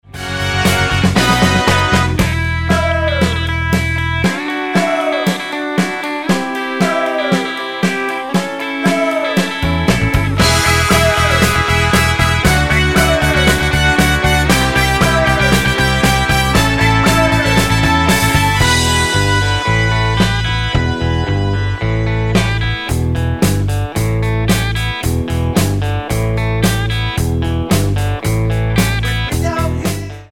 --> MP3 Demo abspielen...
Tonart:E mit Chor